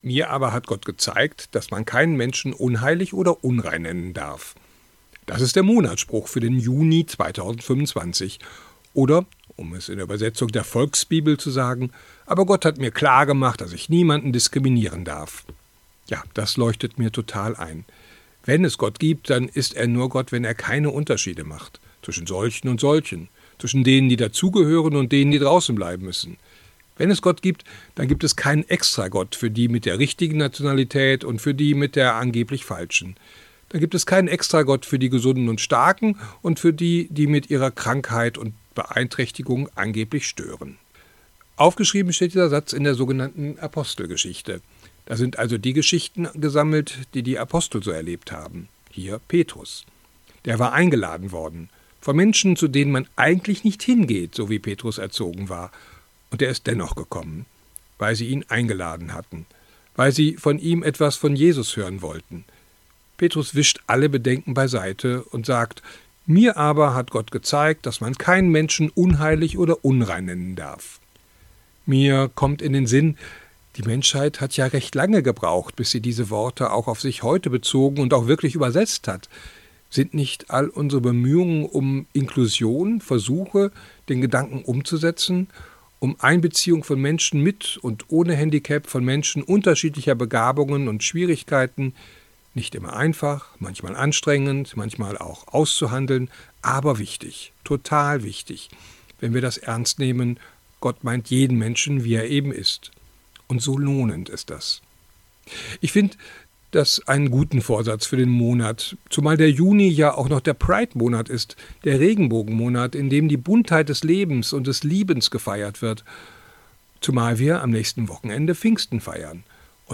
Radioandacht vom 2. Juni